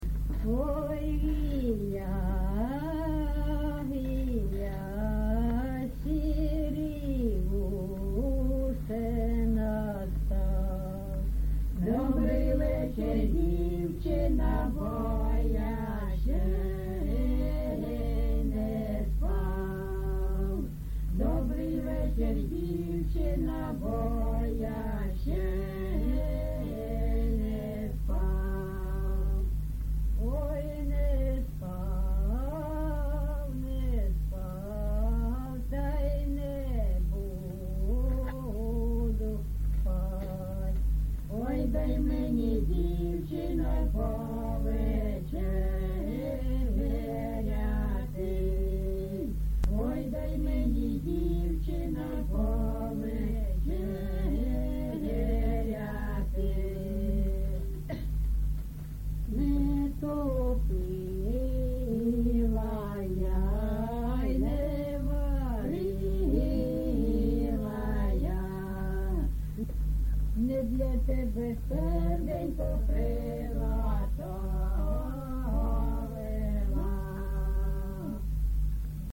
ЖанрПісні з особистого та родинного життя
Місце записус. Званівка, Бахмутський район, Донецька обл., Україна, Слобожанщина